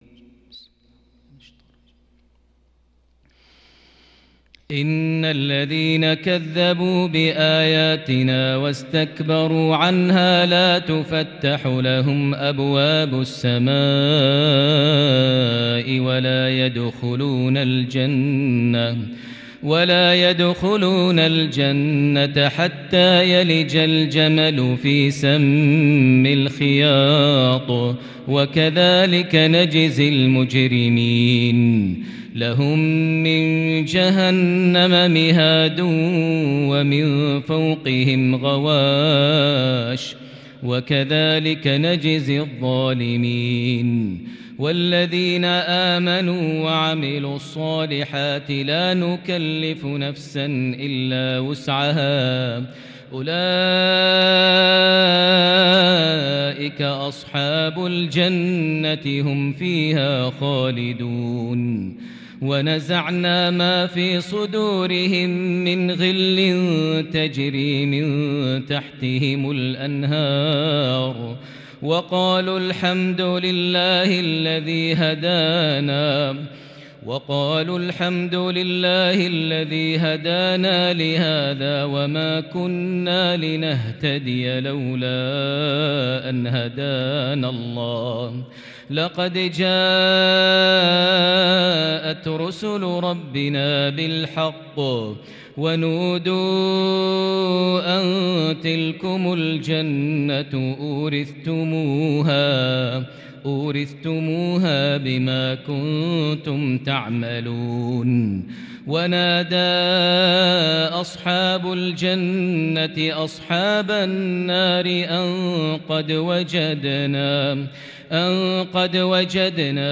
عشائية بديعة للغاية لقصة أصحاب الأعراف (40-52) | 24 جمادى الآخر 1442هـ > 1442 هـ > الفروض - تلاوات ماهر المعيقلي